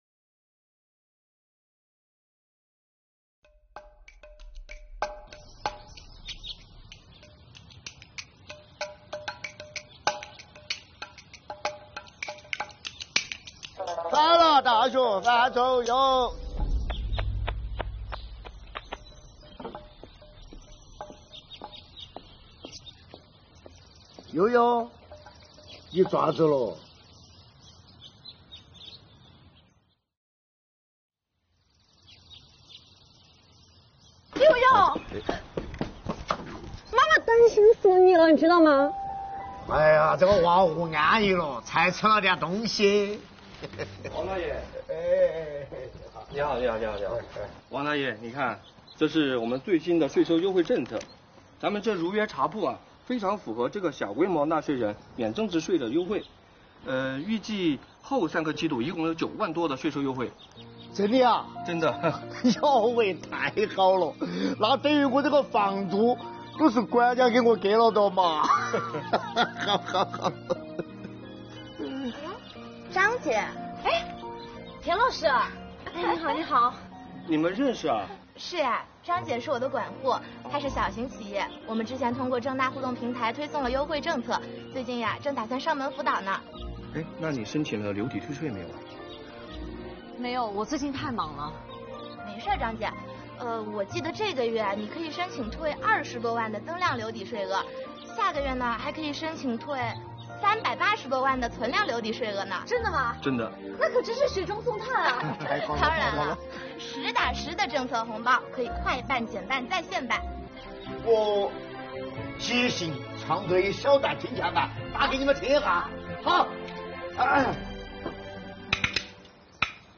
作品根据真实纳税人案例改编，以“如约茶馆”为场景，讲述了单亲妈妈面临家庭、事业困境时，国家退税减税政策及时帮助她解决忧愁，助力小微企业纾困解难。作品最后茶馆老板的一段金钱板演唱激发读者观看兴趣。